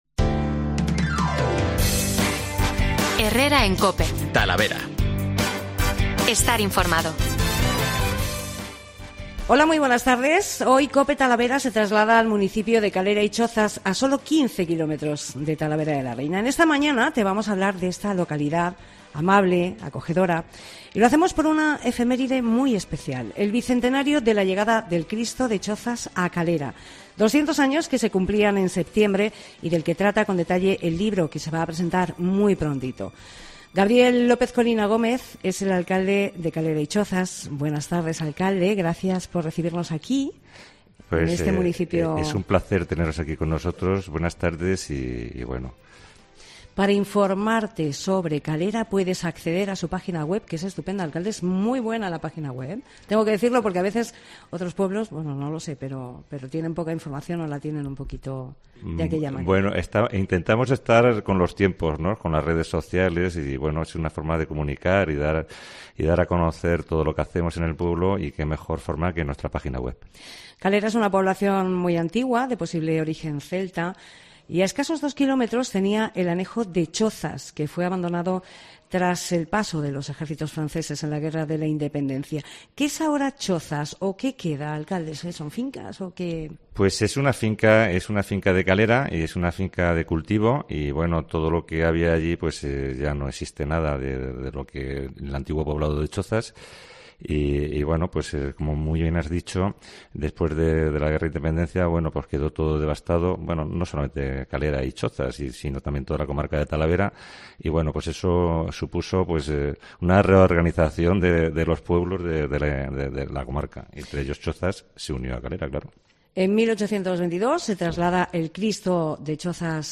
COPE Talavera se traslada al municipio de Calera y Chozas, a solo 15 kilómetros de Talavera.
Hablamos de esta localidad amable y acogedora, con el alcalde Gabriel López Colina, por una efemérides muy especial. El Bicentenario de la llegada del Cristo de Chozas a Calera. 200 años se cumplían en el mes de septiembre y del que trata con detalle el libro que se va a presentar este viernes, 2 de diciembre.